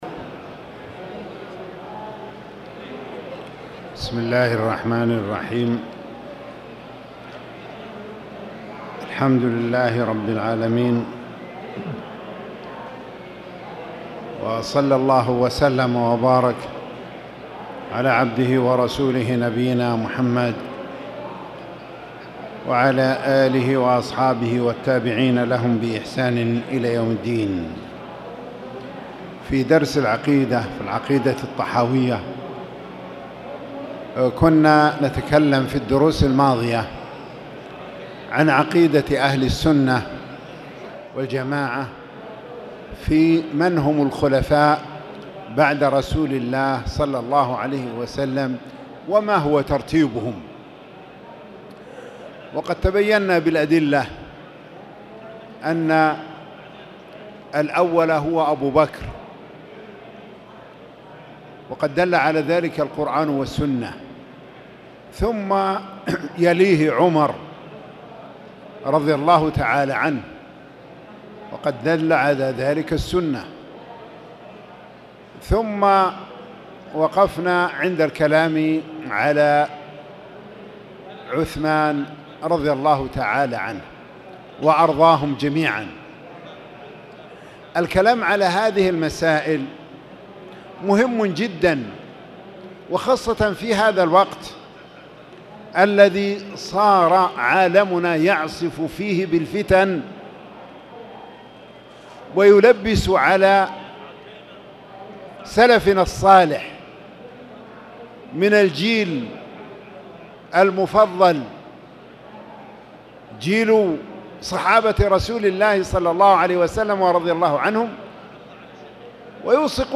تاريخ النشر ٢٨ صفر ١٤٣٨ هـ المكان: المسجد الحرام الشيخ